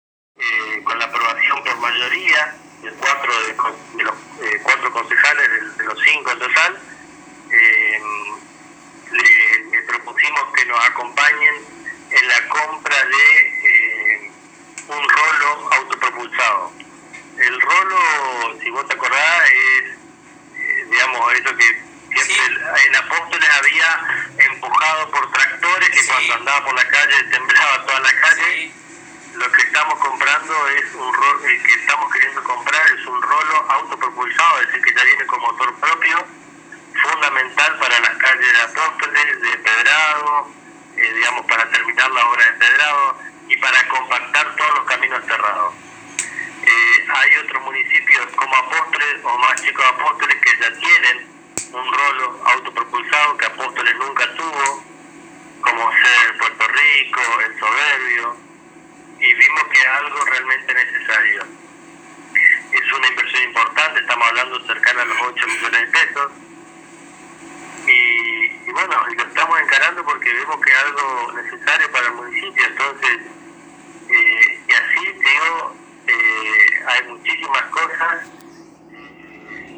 Lo adelantó el Secretario de Hacienda en «Lo mejor de la Ciudad» y a la ANG.